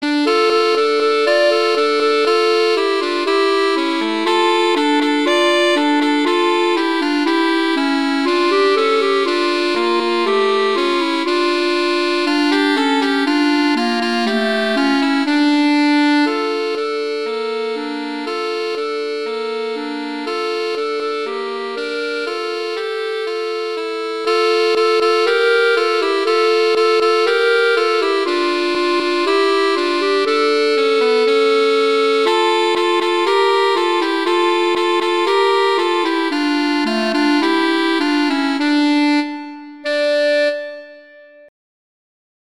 easy duet
winter, holiday, hanukkah, hymn, sacred, children